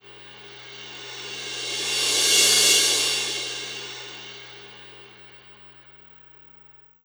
Cymbol Shard 17.wav